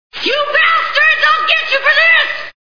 Raiders of the Lost Ark Movie Sound Bites